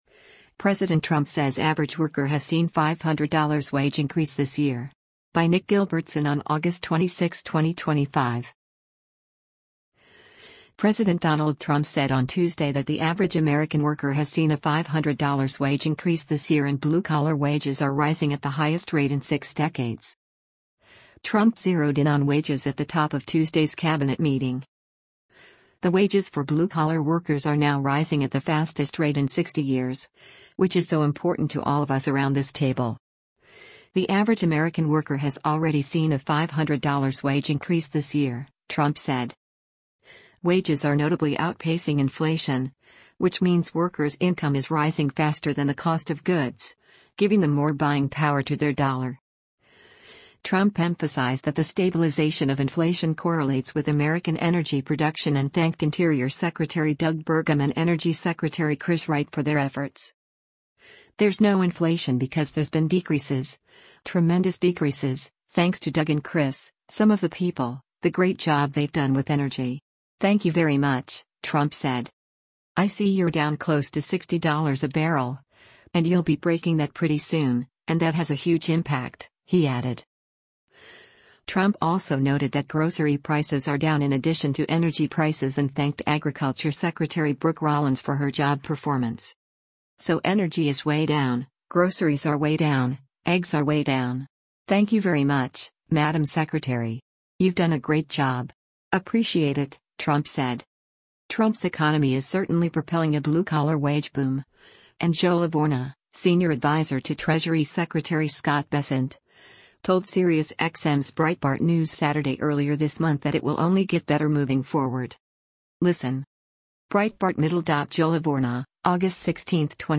Trump zeroed in on wages at the top of Tuesday’s Cabinet meeting.